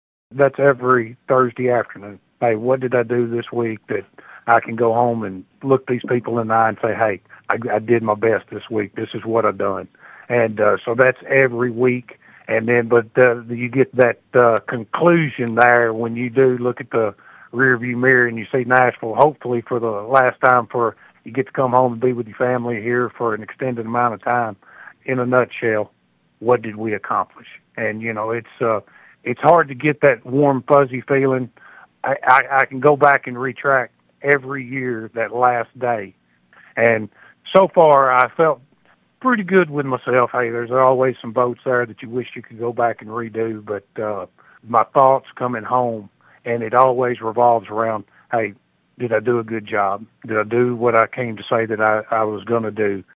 Darby said the betterment of state residents was his goal at each session.(AUDIO)